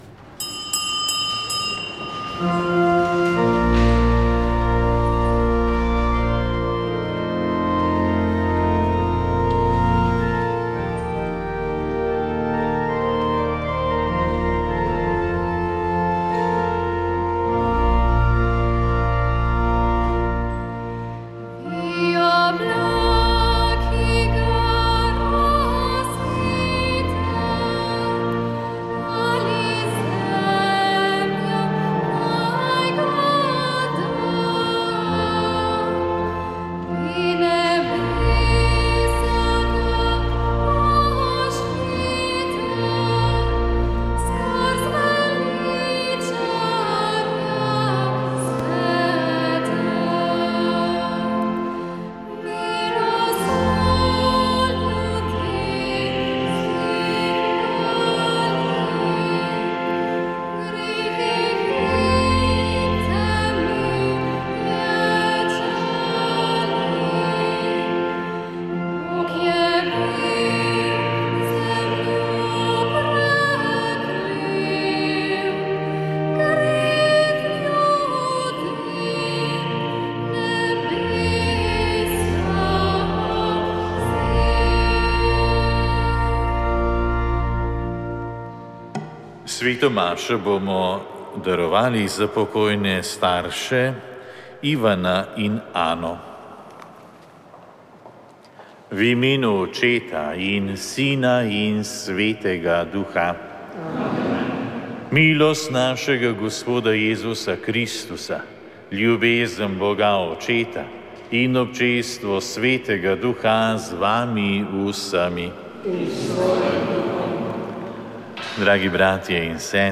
Sveta maša
Sv. maša iz cerkve Marijinega oznanjenja na Tromostovju v Ljubljani 2. 6.